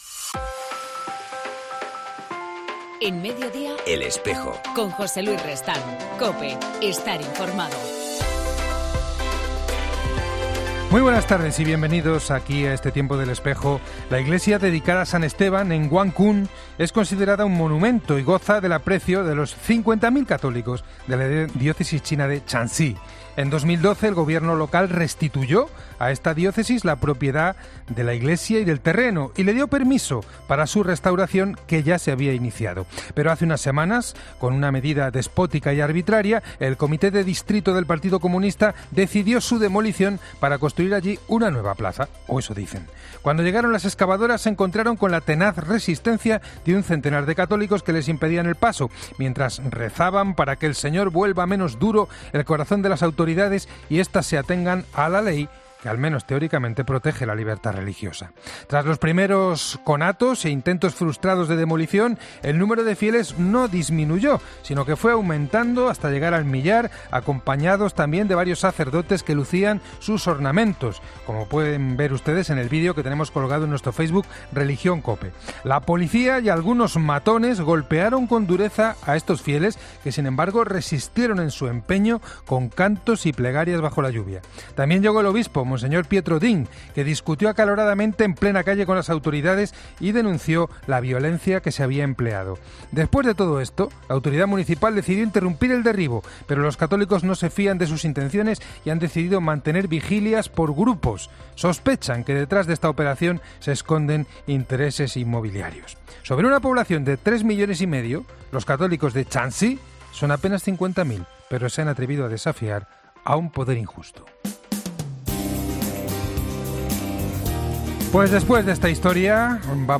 En El Espejo del 6 de septiembre hablamos con Octavio Ruiz Arenas, Secretario del Pontificio Consejo para la Promoción de la Nueva Evangelización